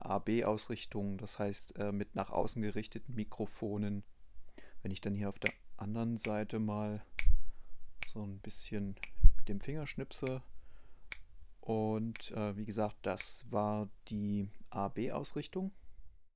Genau wie beim 7er-Modell kommen die Mics hier in Nierencharakteristik daher und sind um 90° schwenkbar für ein mehr oder minder breites Stereobild (AB- versus XY-Ausrichtung).
Die Aufnahmen sind detailliert, ausgewogen und rauscharm.
AB-Ausrichtung
tascam_dr-40x_test__ab-ausrichtung.mp3